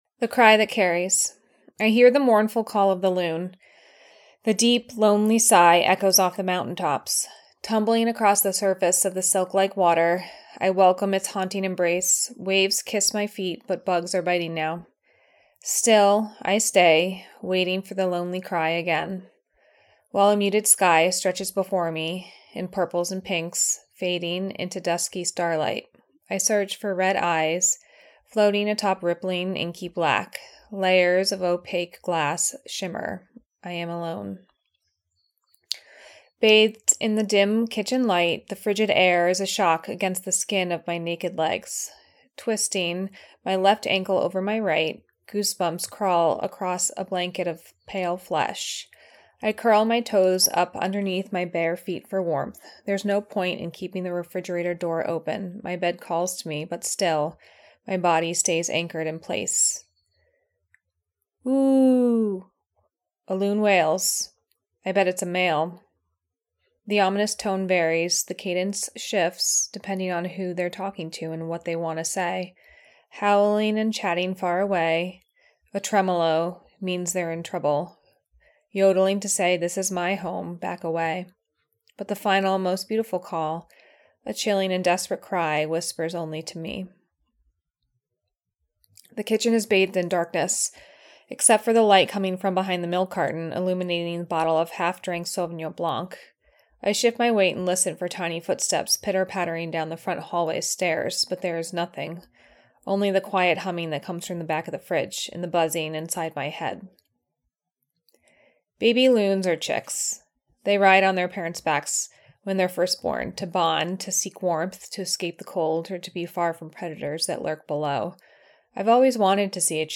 Press Play to hear the author read their piece.